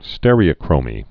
(stĕrē-ə-krōmē, stîr-)